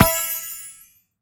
CollectModule_Res_booster_collect收集.mp3